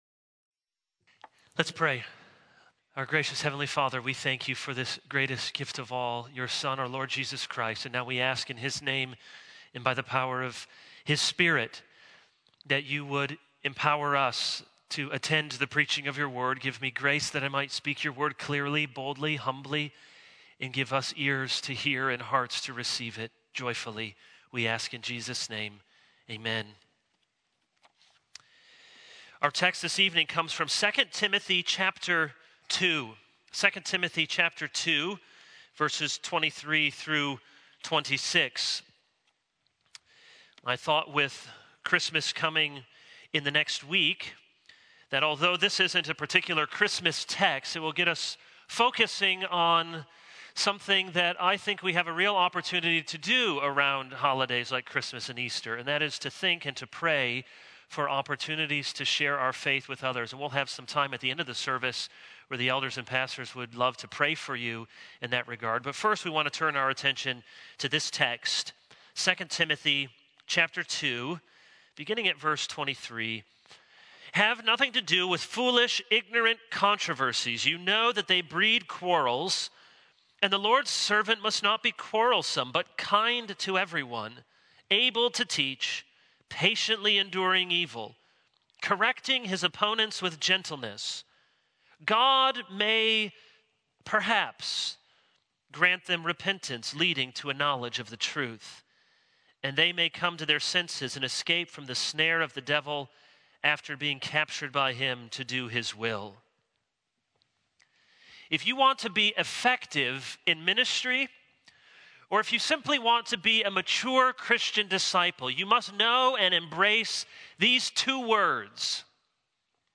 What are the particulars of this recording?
December 17, 2017 | Sunday Evening